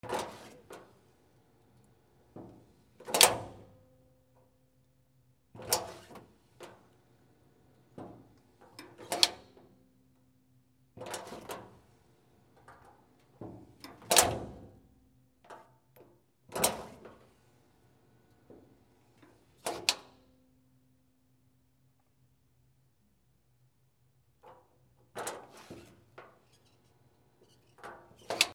/ K｜フォーリー(開閉) / K05 ｜ドア(扉)
ワンルームマンションのドアを開け閉め